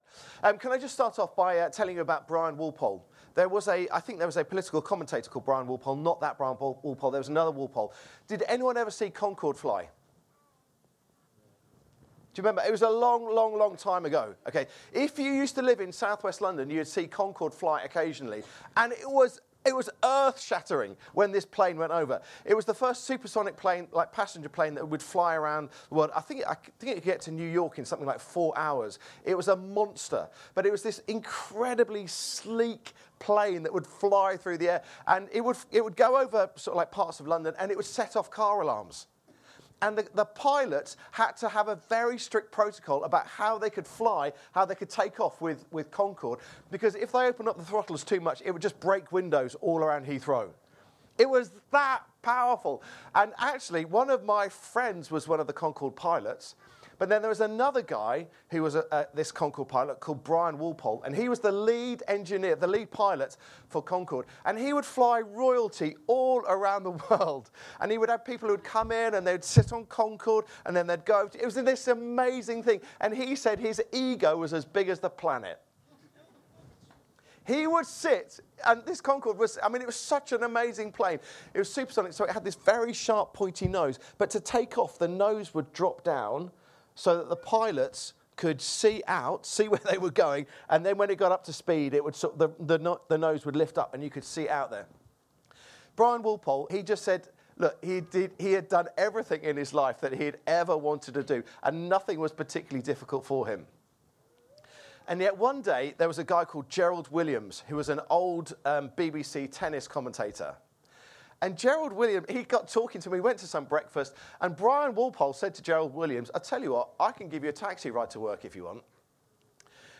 Download Paul on the road to Damascus | Sermons at Trinity Church